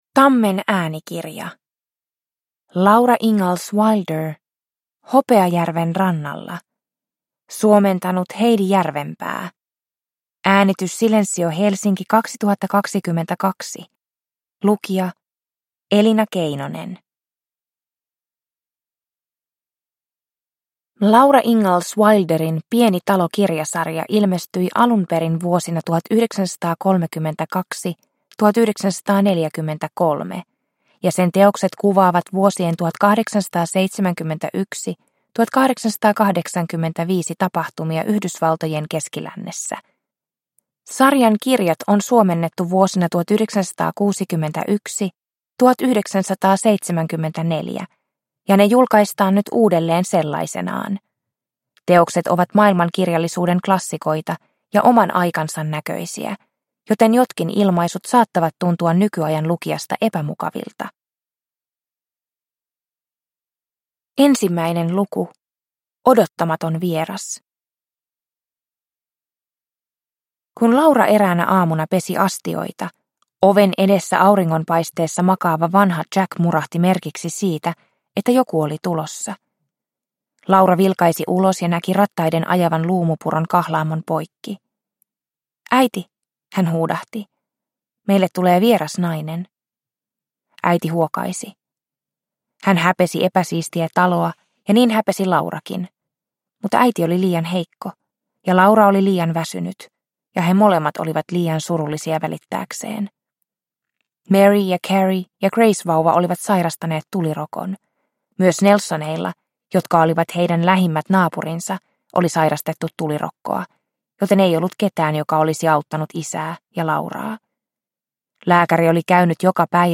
Hopeajärven rannalla – Ljudbok – Laddas ner